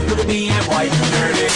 Worms speechbanks
Firstblood.wav